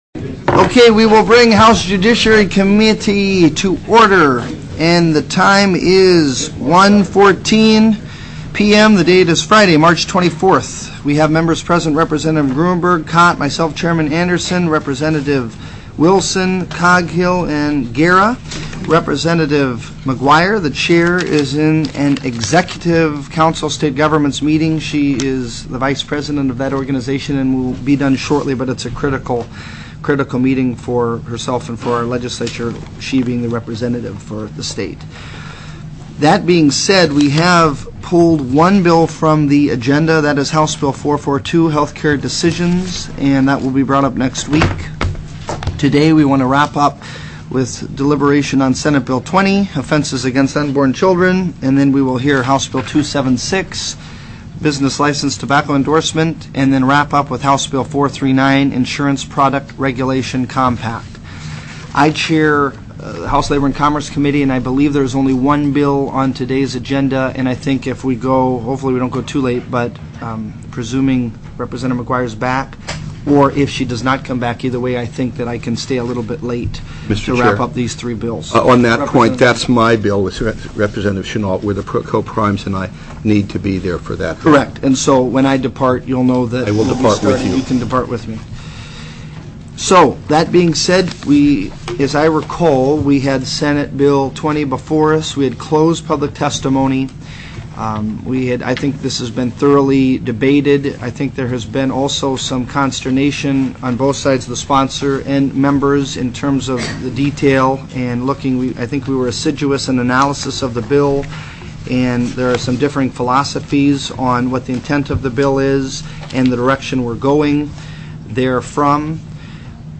03/24/2006 01:00 PM House JUDICIARY